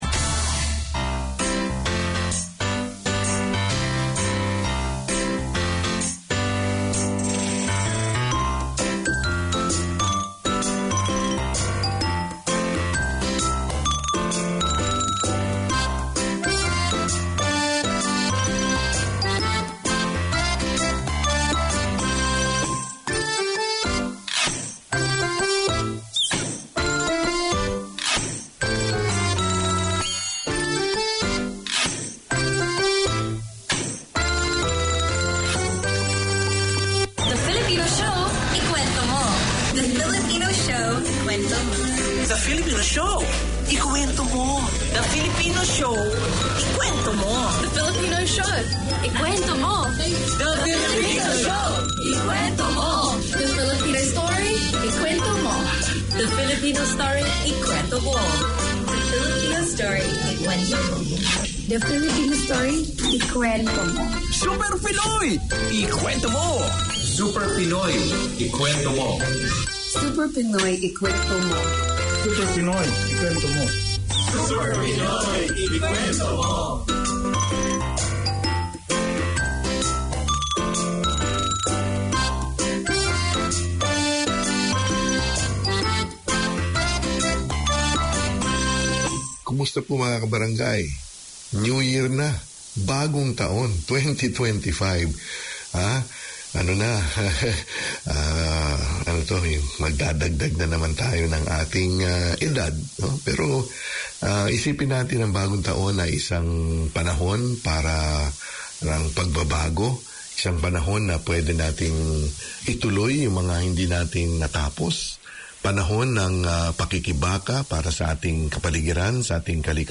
Community Access Radio in your language - available for download five minutes after broadcast.
The Filipino Show 12:40pm WEDNESDAY Community magazine Language